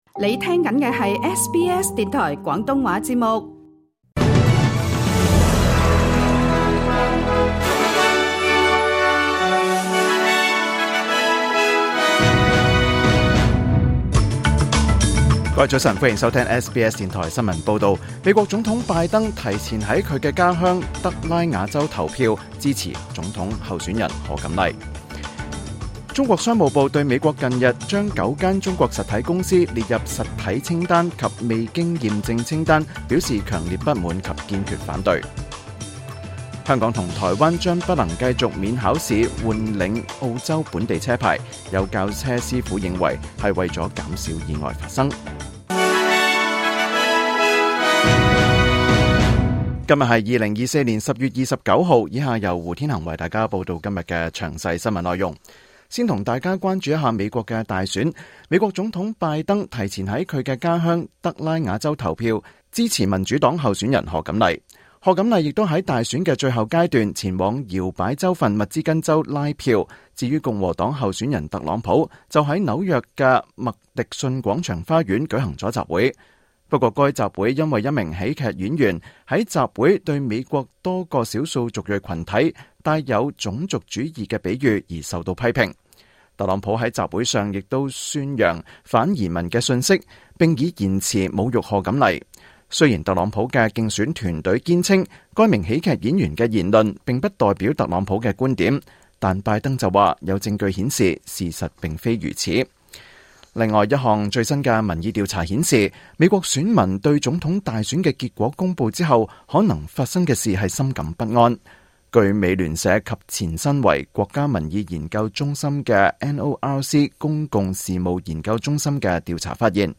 2024 年 10 月 29 日 SBS 廣東話節目詳盡早晨新聞報道。